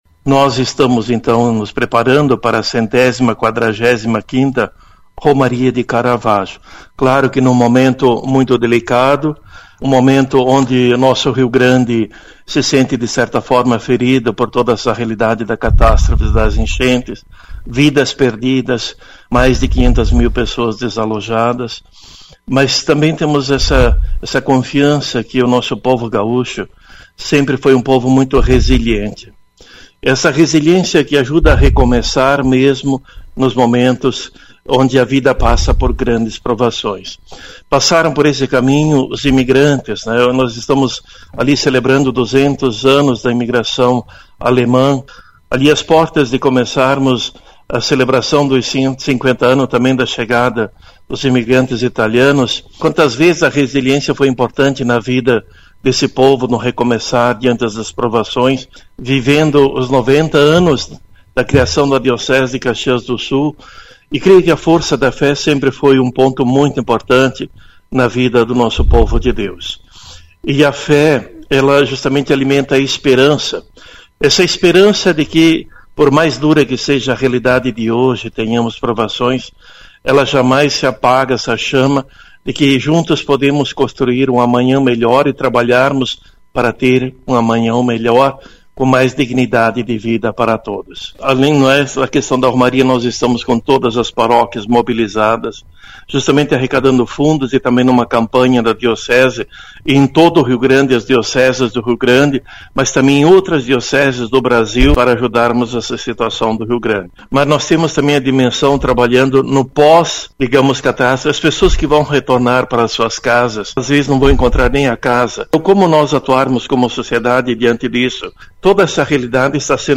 ENTREVISTA-DOM-JOSE-GISLON-24-05-2.mp3